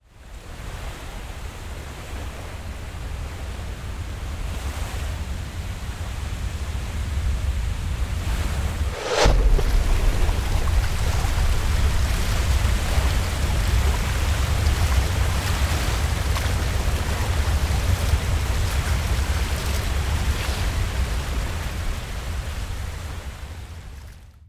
Кинематографический гул цунами